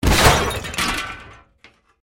Звуки аварий
Аварии – альтернативный вариант